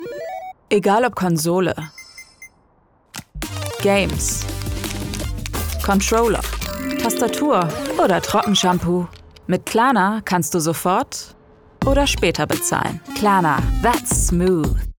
Corporate Videos
I specialize in bringing characters, commercials, and narrations to life with a warm, engaging, and versatile voice.
I work from my professional home studio, ensuring high-quality recordings, fast turnaround times, and a flexible, reliable service.
LowMezzo-Soprano